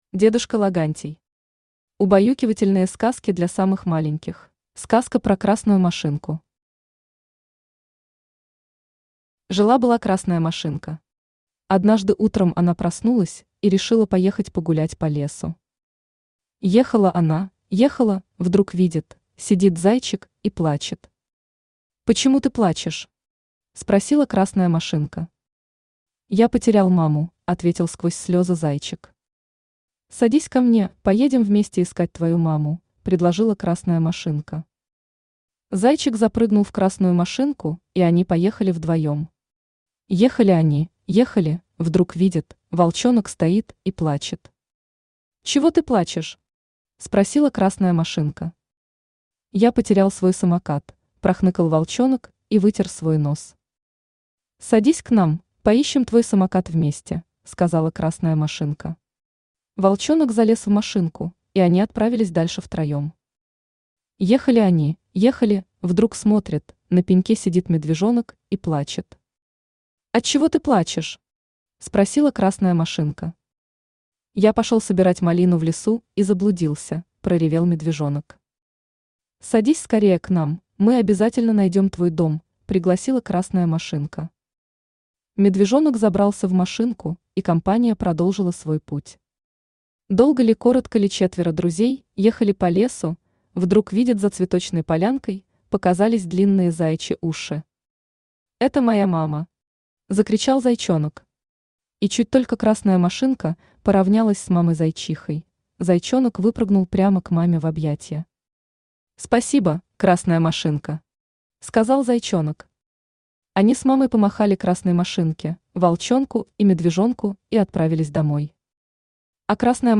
Тихое, тягучее чтение сказок способно убаюкать не только взрослого, но даже самого маленького ребёнка.